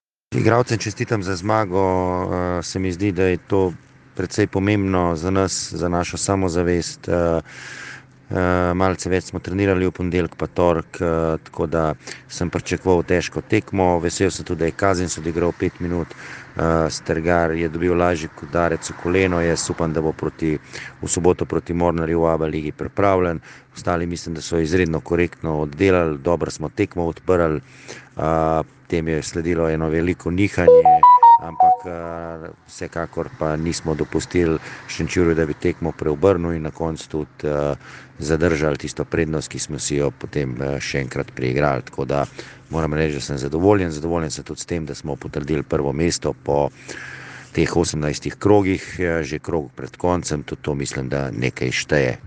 Izjava po tekmi: